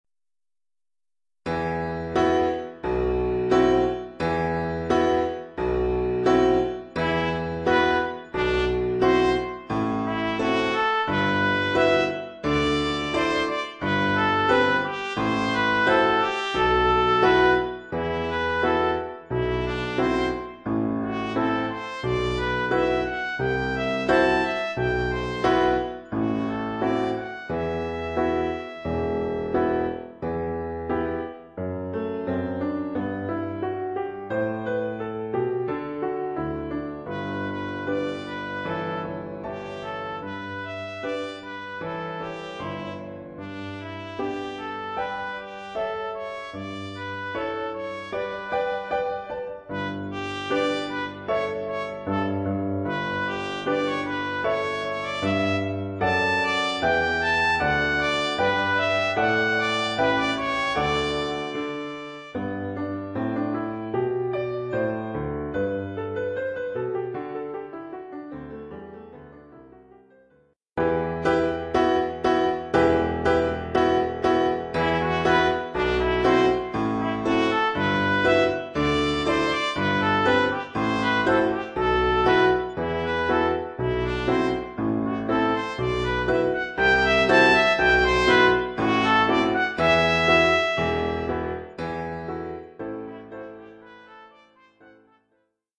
Oeuvre pour trompette ou cornet ou bugle et piano.
cornet ou bugle et piano.